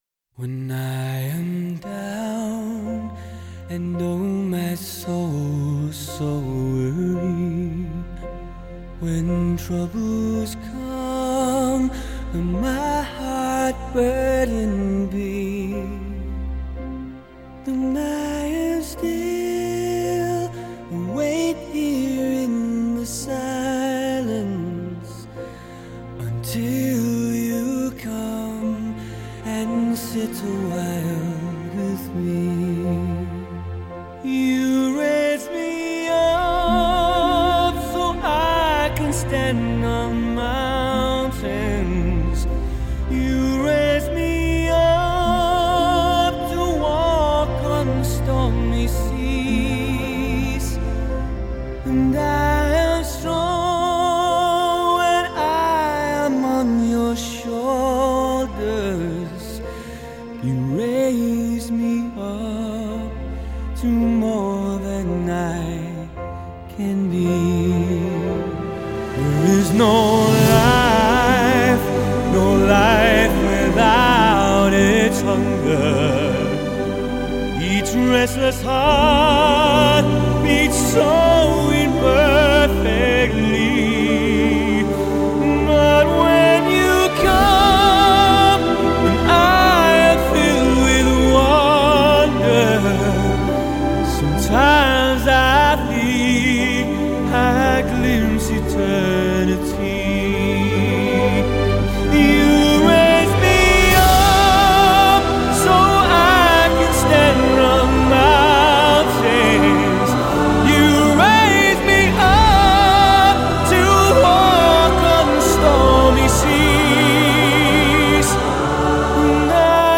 界著名歌手，26首流传于世的经典名唱，音色极佳的 DSD 数码录制，使我们享受到103分钟的终极跨界金声！
史上最畅销古典跨界歌王经典代表作